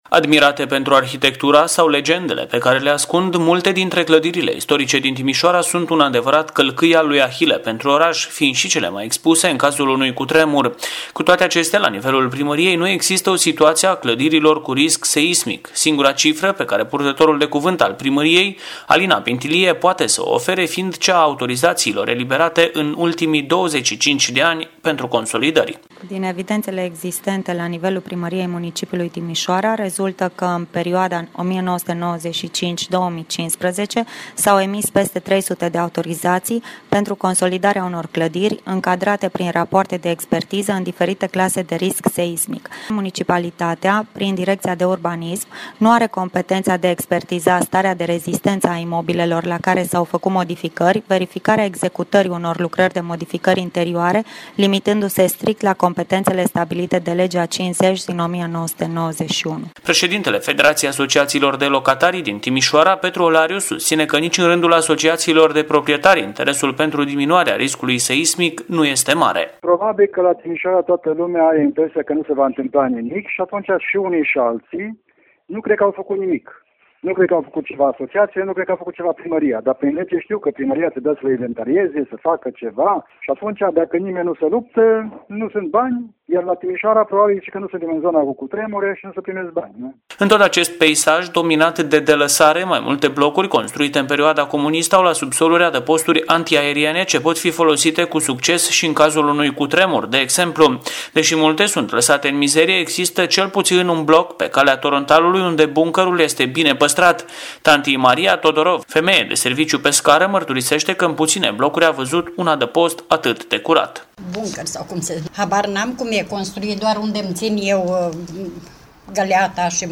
Ascultaţi în format audio declaraţiile reprezentanţilor Primăriei Timişoara şi ai FALT